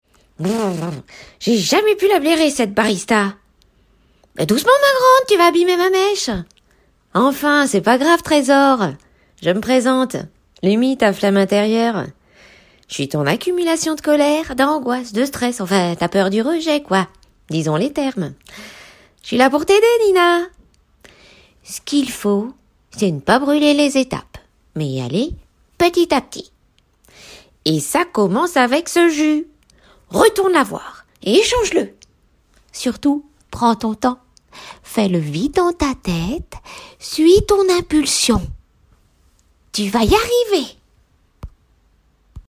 Proposition voix personnage dessin animé Lumy la flamme!